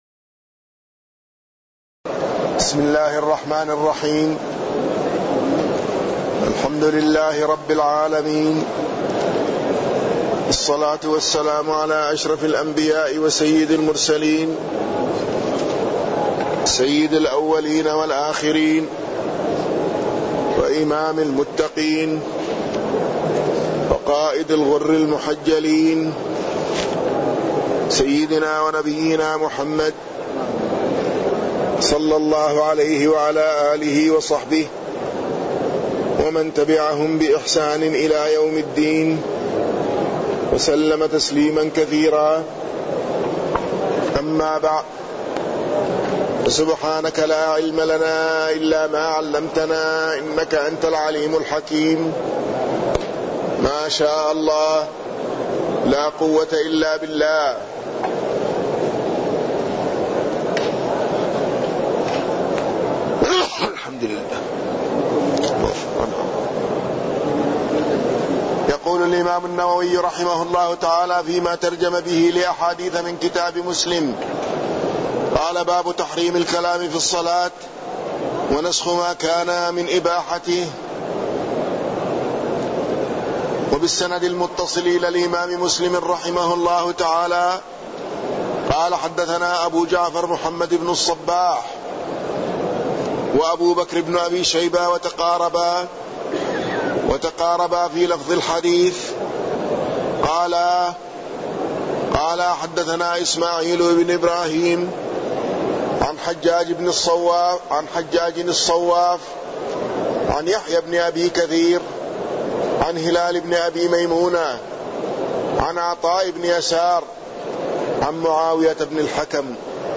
تاريخ النشر ٧ جمادى الأولى ١٤٢٩ هـ المكان: المسجد النبوي الشيخ